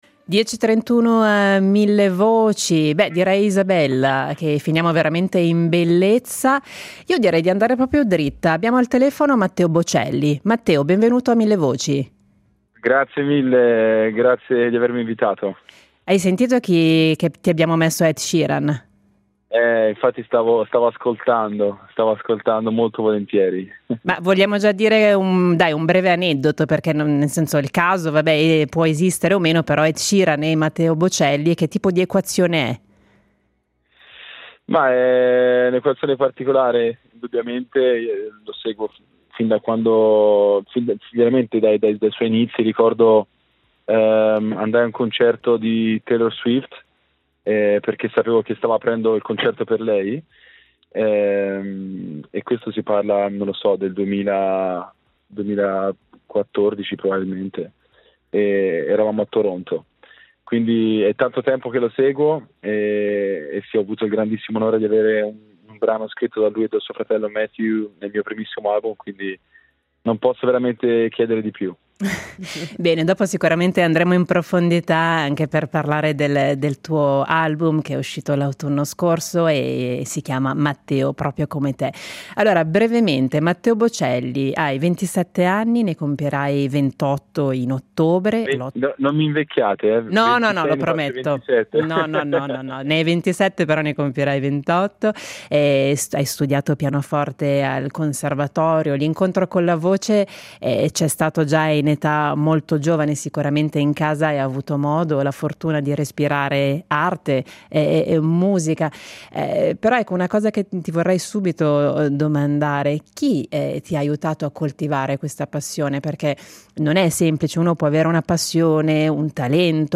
Intervista a Matteo Bocelli